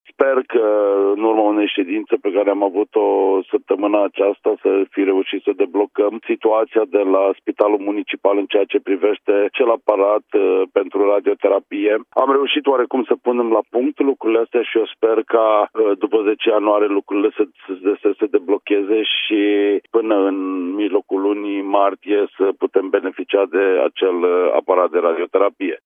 Administraţia publică din Timişoara a reuşit deblocarea proiectului de construcţie a buncărului pentru radiologie de la Clinicile Noi. Viceprimarul Cosmin Tabără a anunţat, în direct la Radio Timişoara, că lucrările vor intra în linie dreaptă după 10 ianuarie.